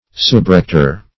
Subrector \Sub*rec"tor\, n. An assistant restor.